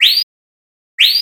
Les trois exemples ci-dessous sont des paires d'extraits de chants de flamant, de piauhau et d'oriole.
Piauhau sp.